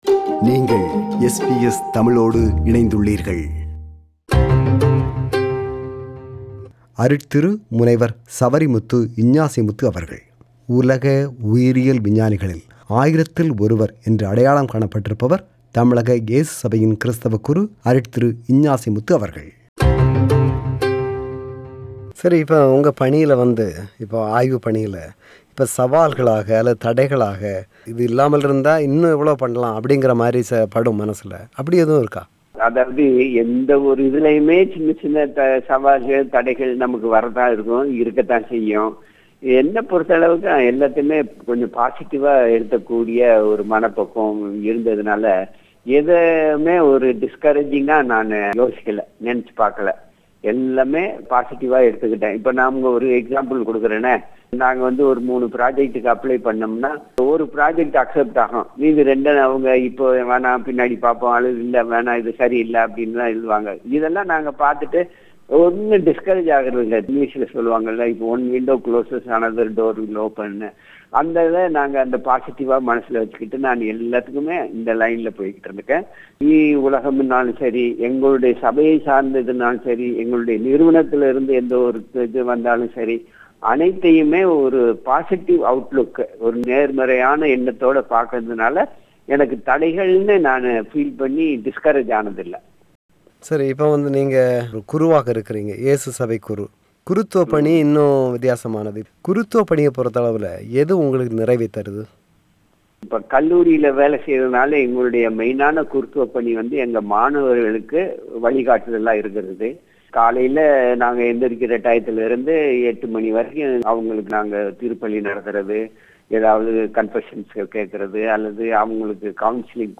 சந்தித்து உரையாடுகிறார்